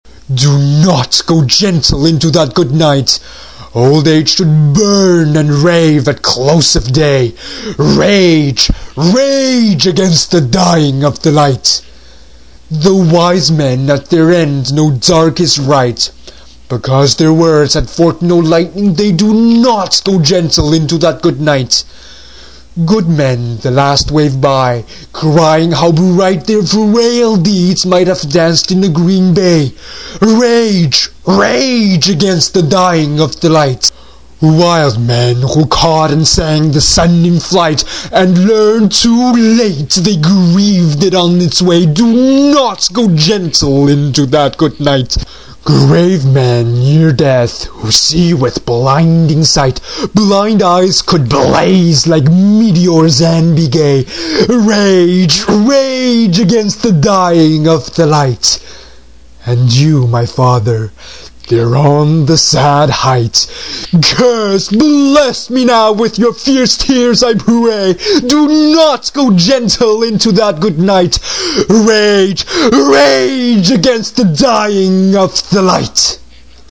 It's kind of like singing, except you can put even more energy and passion into reciting, and the adrenaline rush you get is pretty incredible!
English Recitation